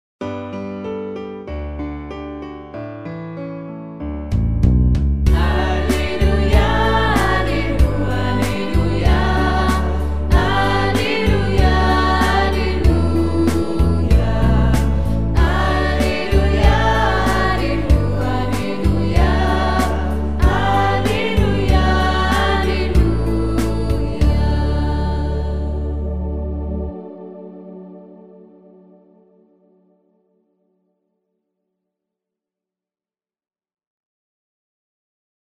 Chants divers
alleluia_1_chant.mp3